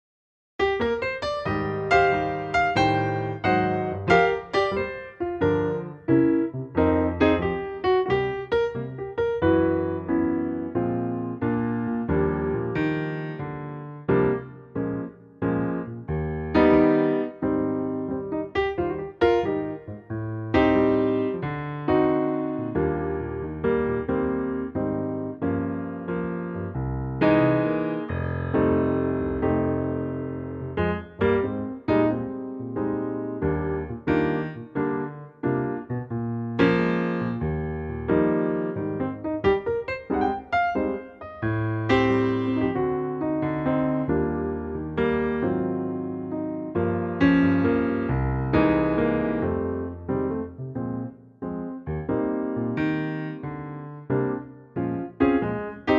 Unique Backing Tracks
key - Eb - vocal range - G to G
Wonderful piano arrangement of this classic old standard.